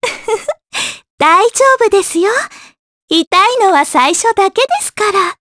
Mediana-vox-dia_02_jp.wav